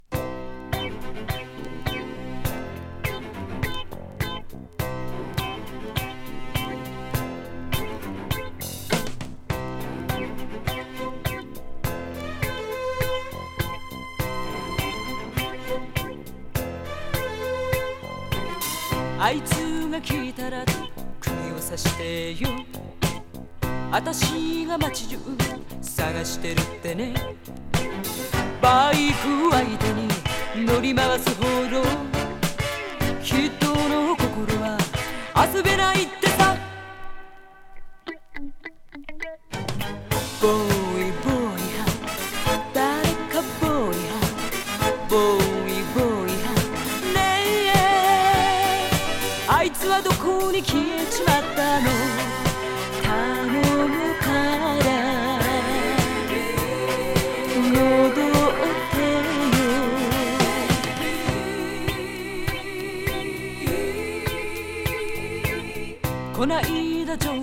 Japanese 和ソウル / ディスコ / ファンク レコード